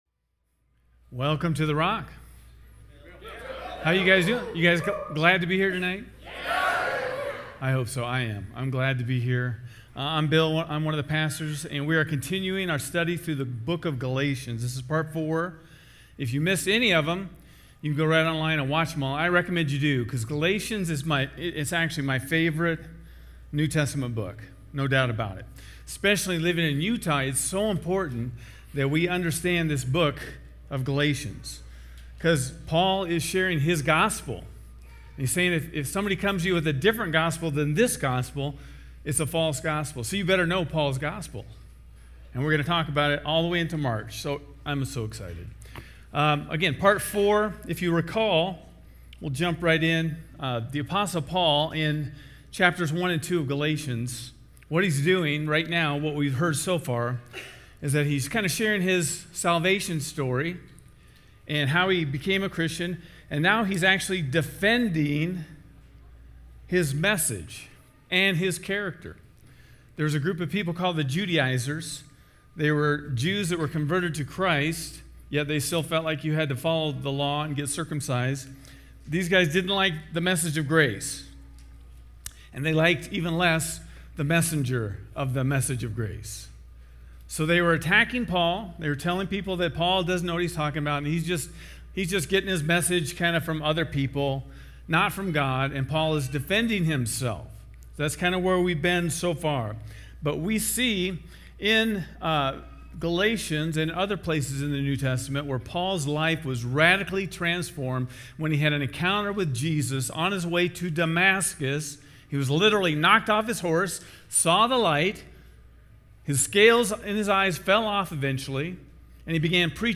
In Galatians, the Apostle Paul shared his personal journey of transformation, showing how the Gospel radically changed lives. In this sermon, we will explore three key points that illustrate the transformative power of the Gospel in Paul's life.